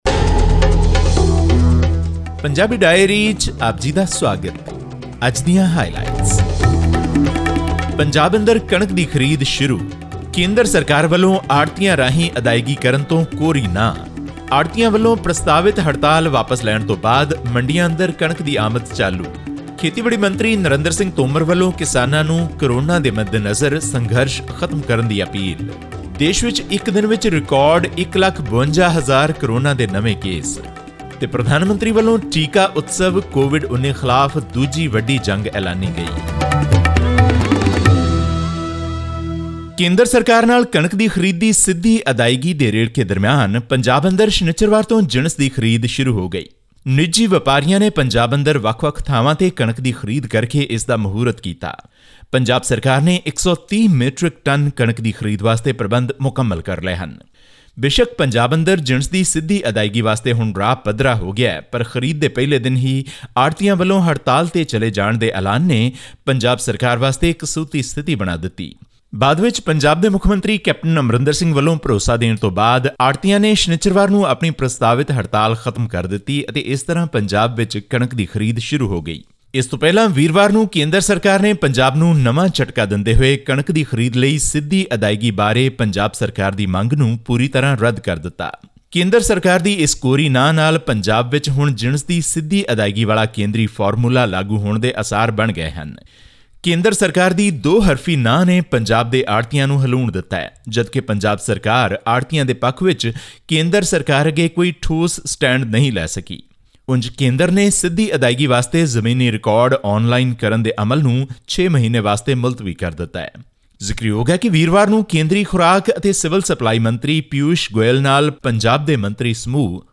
As the coronavirus situation continues to escalate in India, Union Agriculture Minister Narendra Singh Tomar has appealed to farmers protesting at New Delhi's borders to end their agitation. This and more in our weekly news segment from Punjab.